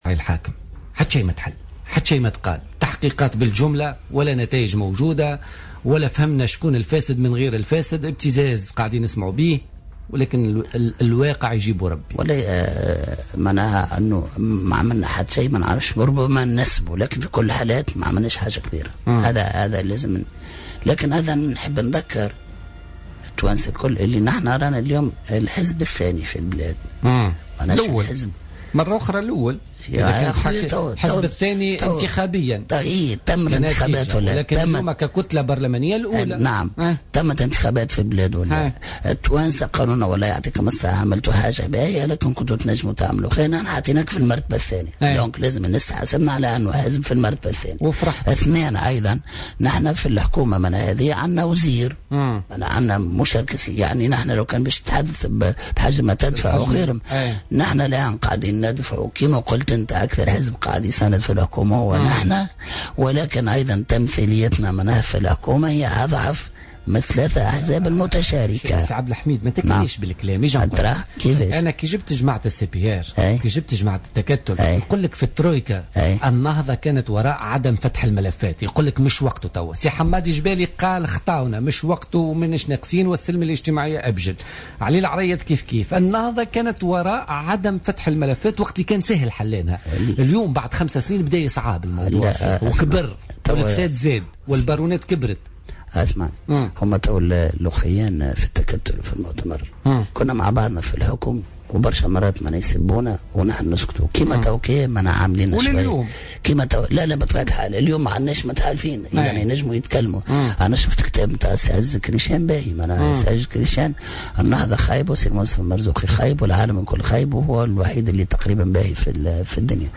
أكد نائب رئيس حركة النهضة عبد الحميد الجلاصي ضيف بوليتيكا اليوم الأربعاء 27 أفريل 2016 أن حركة النهضة هي الحزب الثاني في البلاد ولا يمكنها أن تفتح ملف الفساد الذي لم تخطو فيه تونس أشواطا مهمة .